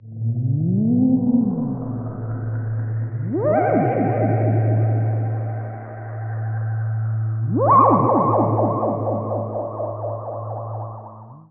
Theremin_Atmos_04.wav